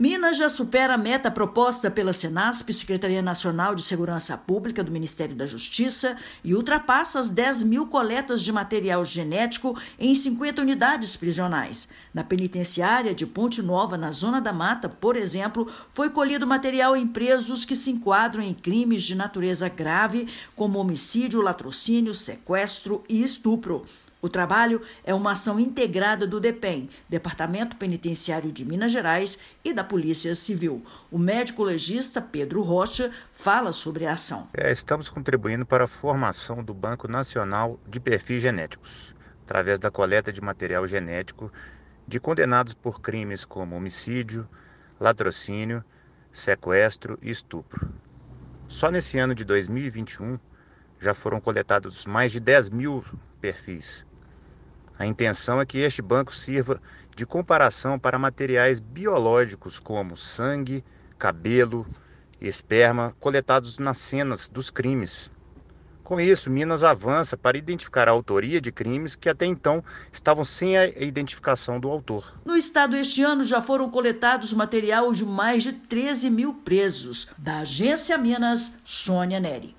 Minas já supera a meta proposta pela Secretaria Nacional de Segurança Pública (Senasp), do Ministério da Justiça e Segurança Pública, e ultrapassa as 10 mil coletas de material genético em 50 unidades prisionais. Ouça a matéria de rádio.